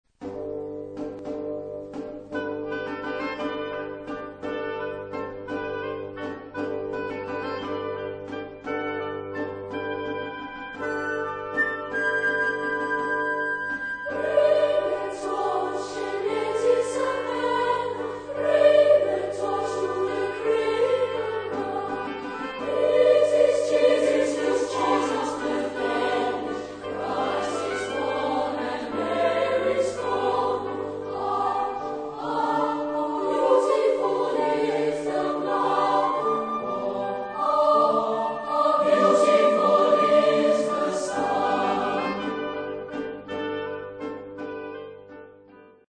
Tipo de formación coral: SATB  (4 voces Coro mixto )
Instrumentos: Piano (1)